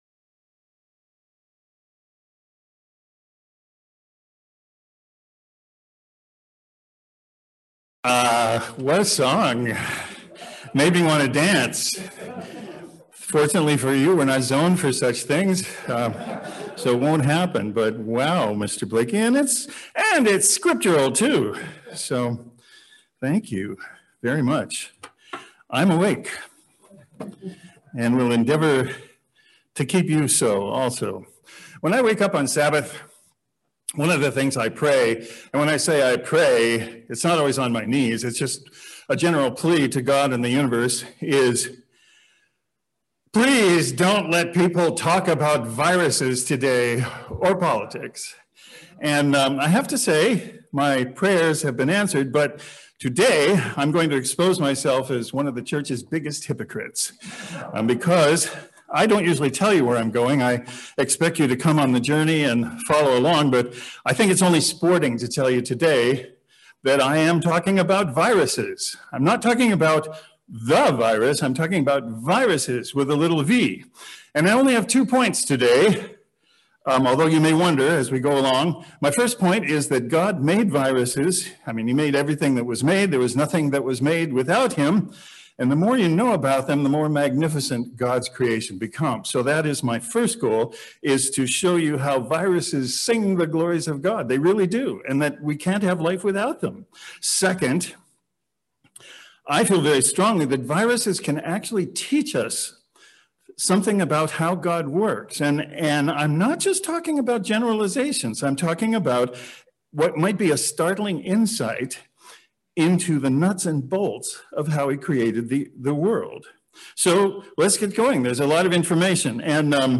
Admittedly, this is a Sermon that may not be of interest to everyone.
Given in Cleveland, OH